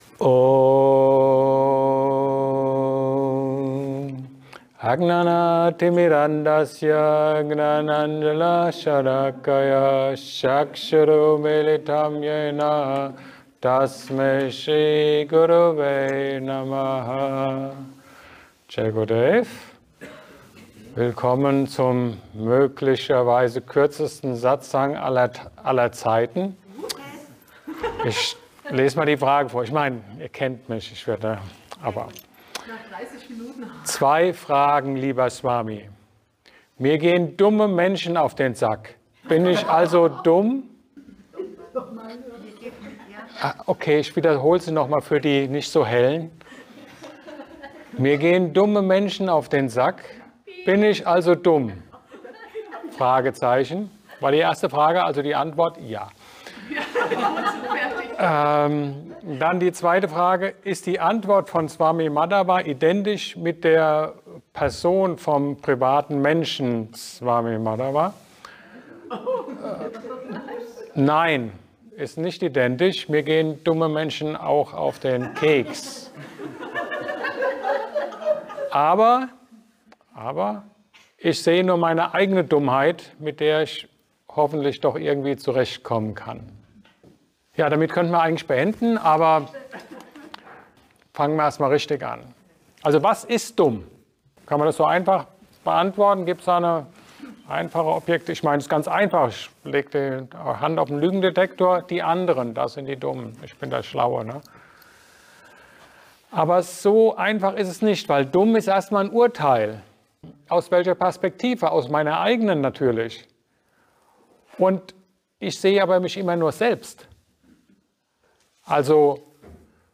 Aktuelles aus dem Sri Vitthal Dham Tempel im Seepark Kirchheim